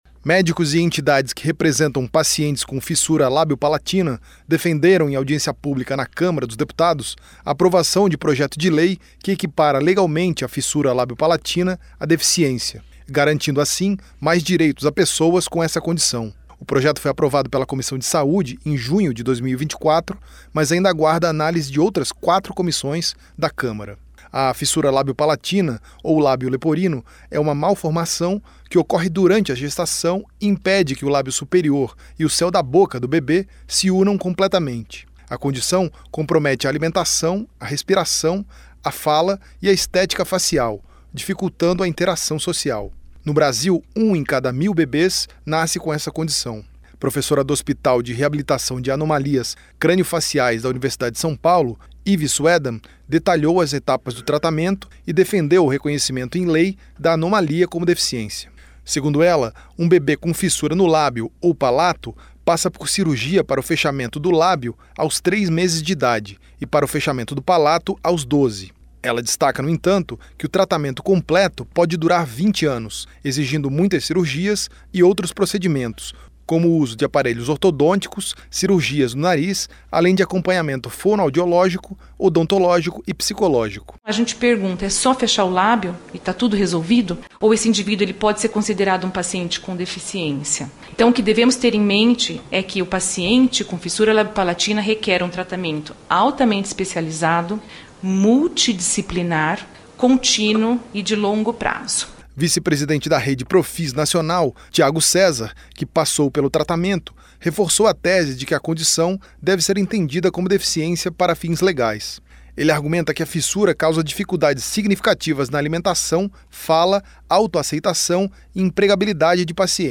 Comissão de Saúde promoveu audiência pública sobre o tema